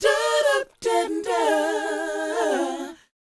DOWOP A#4D.wav